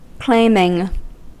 Ääntäminen
Ääntäminen US Haettu sana löytyi näillä lähdekielillä: englanti Käännöksiä ei löytynyt valitulle kohdekielelle. Claiming on sanan claim partisiipin preesens.